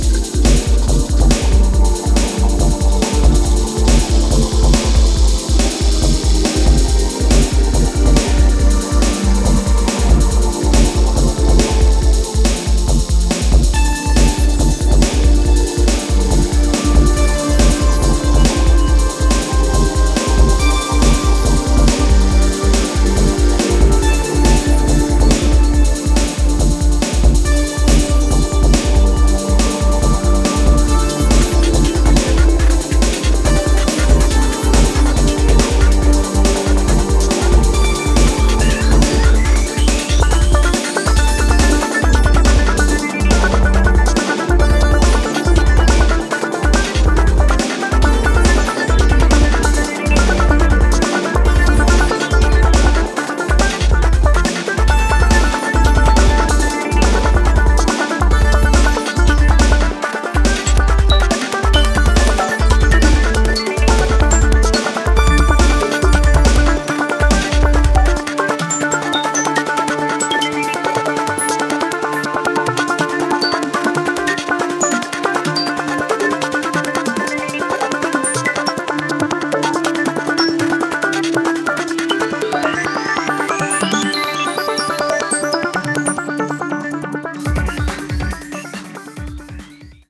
supplier of essential dance music
Electro Acid
Breaks